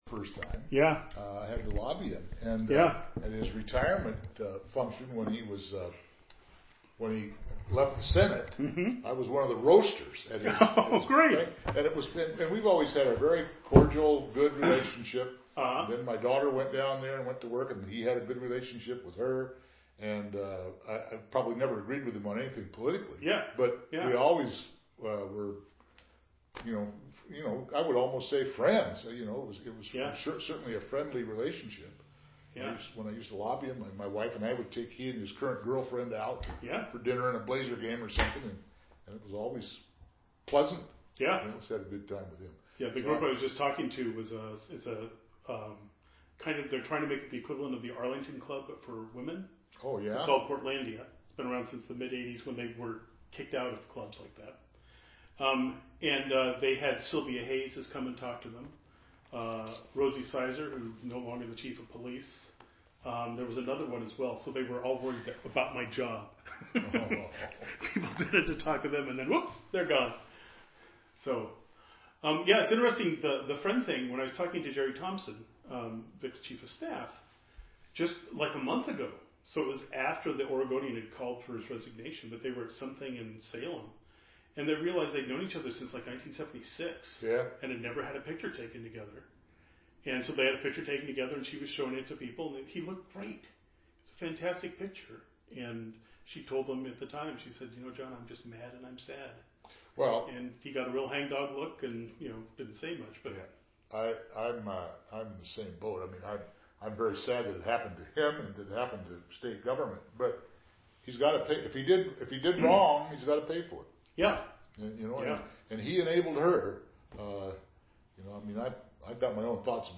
Gary Wilhelms interview on Atiyeh · Victor Atiyeh Collection · heritage